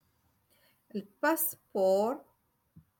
Moroccan Dialect- Rotation Three- Lesson Seventeen